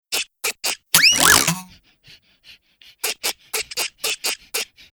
backflip.mp3